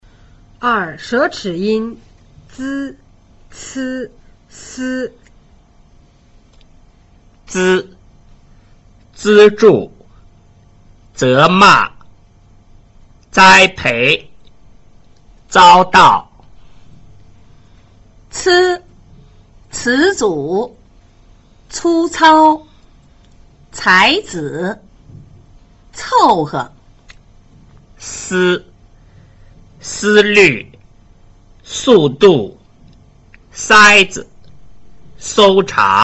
2.  舌齒音  z  c  s
這組是舌尖前音，舌尖向上接近或接觸門齒背，造成氣流阻礙而發出來的聲音。z 是不送氣音，c 是送氣音，s 則是氣流從間隙磨擦通過而發聲。